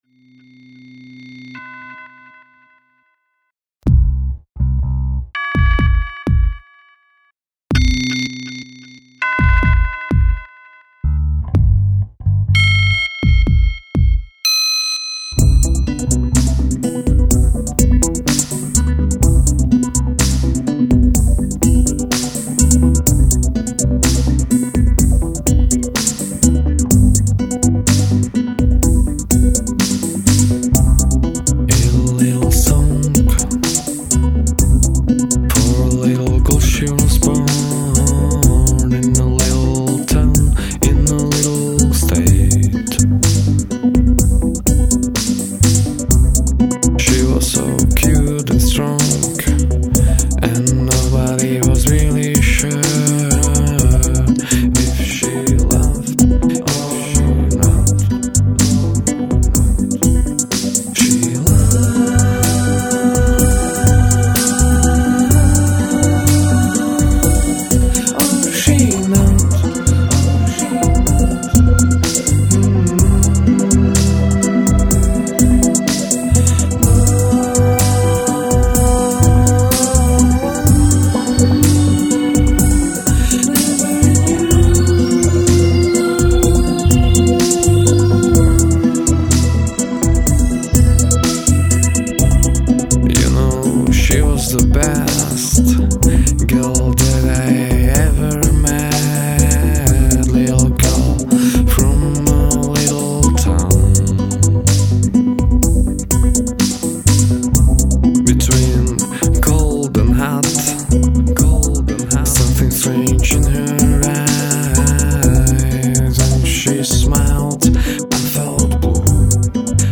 drums and percussions
el. guitars
keyboards
...and me :o) - voc, guitars, bassguitars, fluets, clarinets